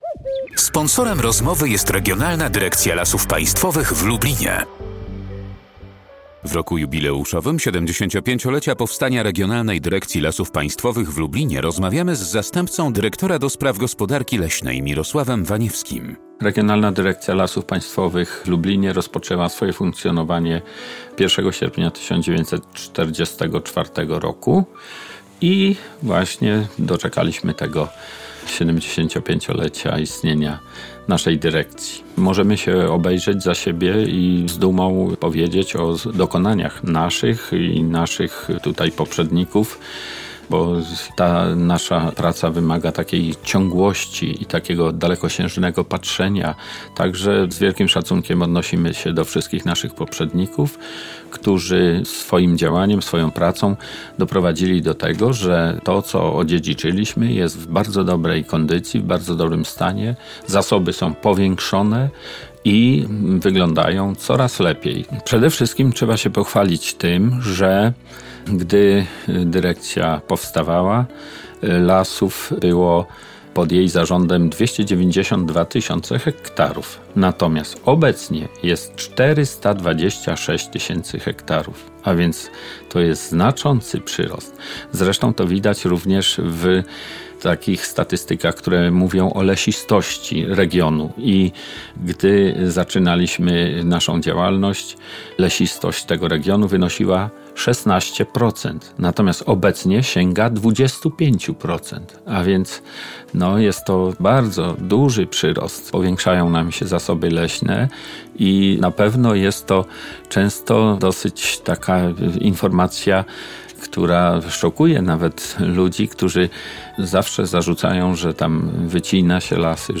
Rozmowy w Radiu Lublin